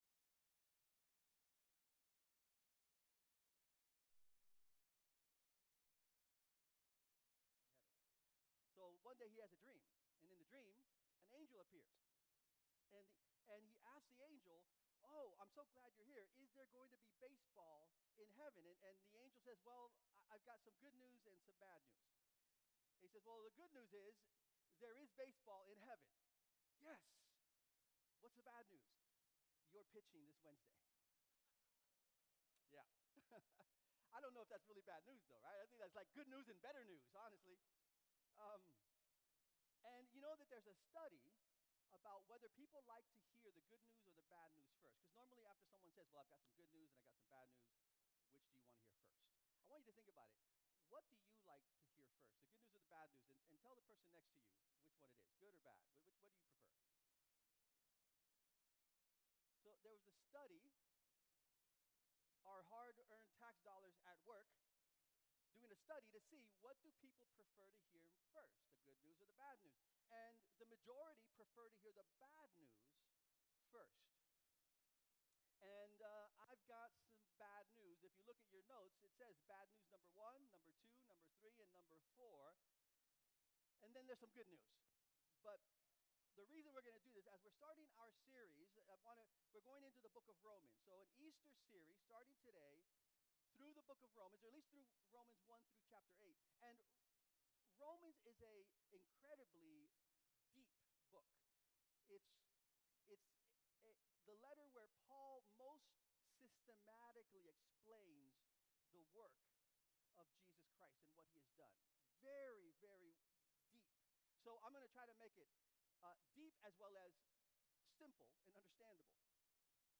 Sermon Archives | Syracuse Alliance Church